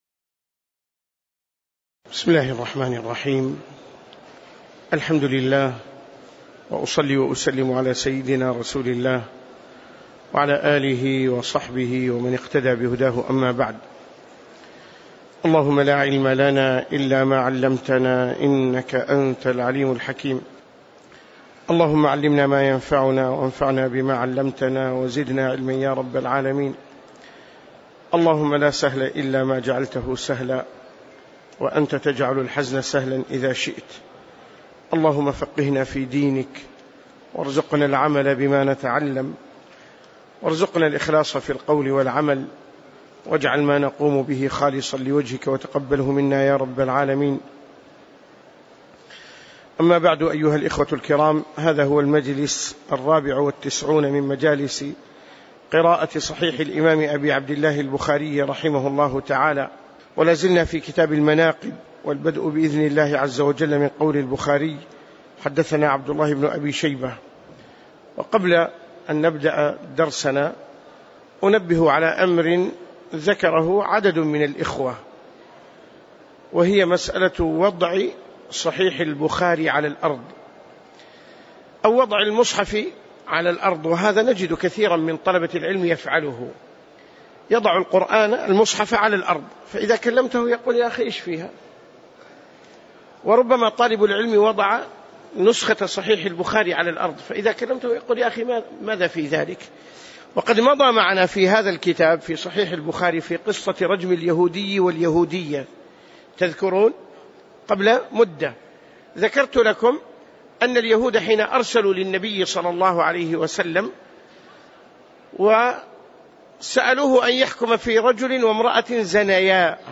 تاريخ النشر ٢ رجب ١٤٣٨ هـ المكان: المسجد النبوي الشيخ